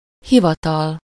Ääntäminen
IPA: /by.ʁo/